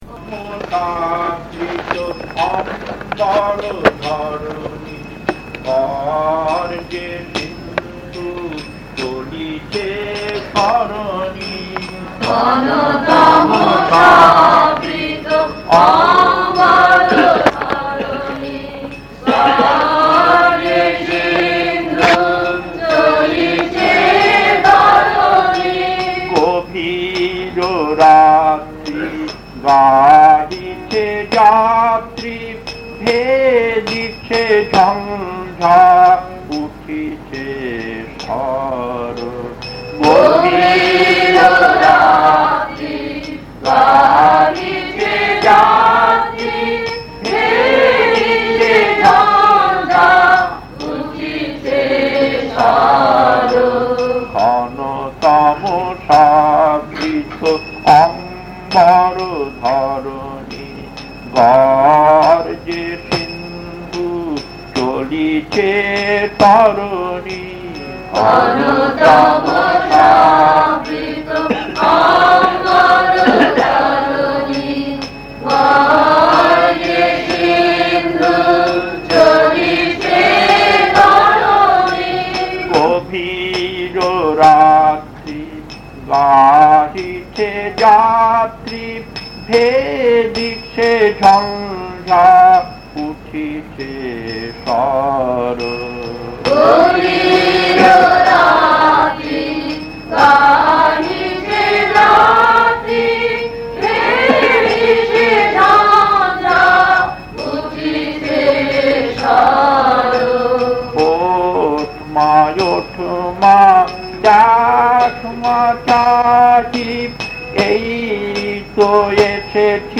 Kirtan A2-2 Puri 1981 1.